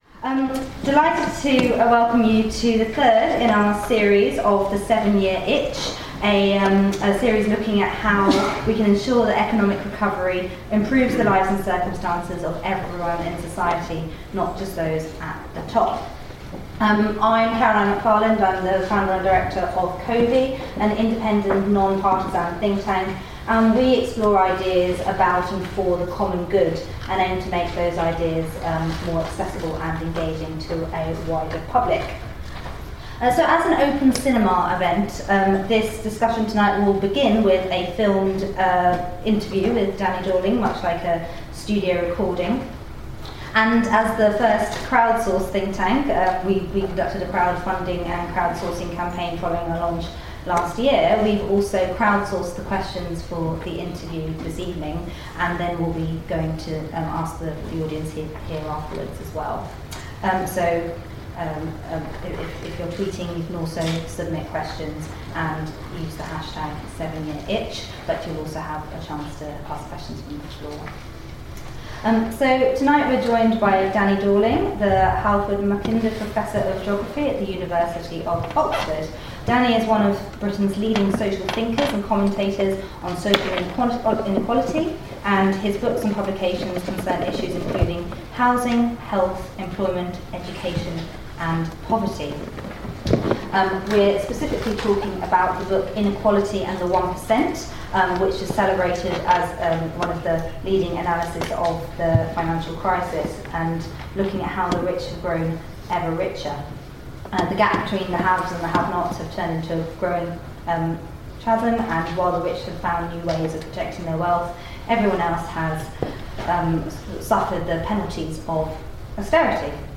CoVi, Somerset House, London, November 26th 2015